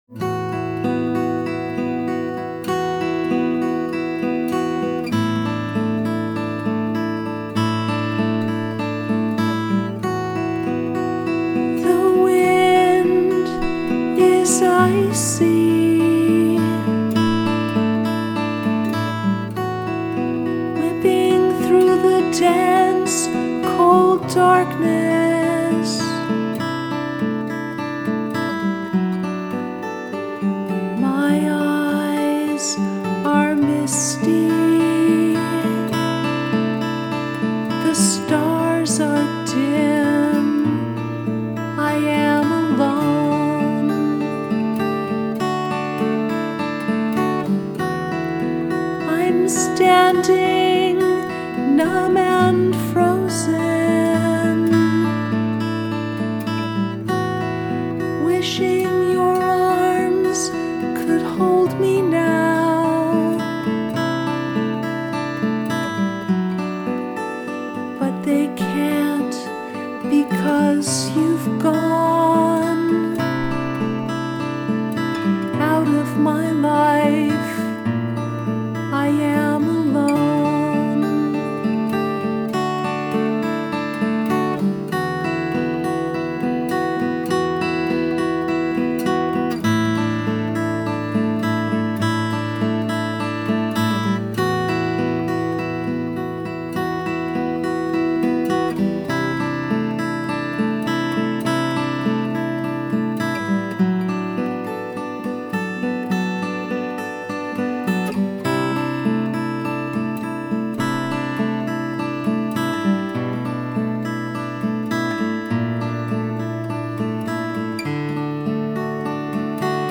Alone Acoustic Home Recording 2017
alone-acoustic-home-recording-2-17-17.mp3